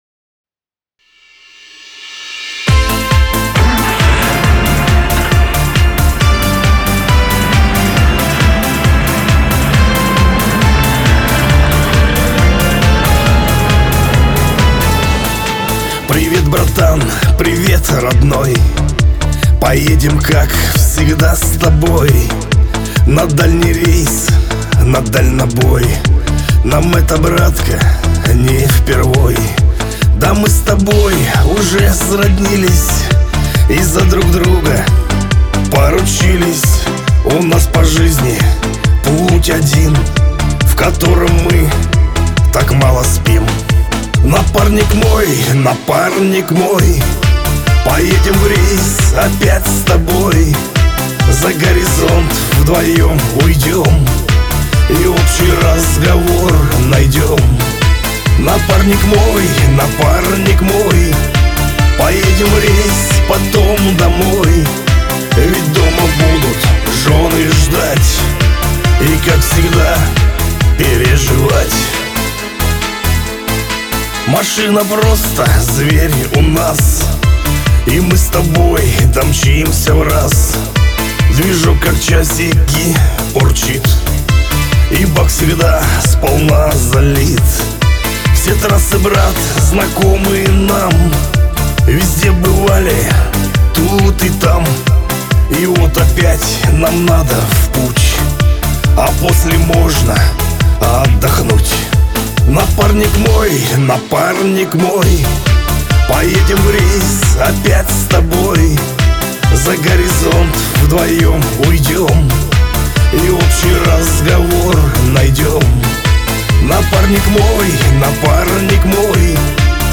диско
Шансон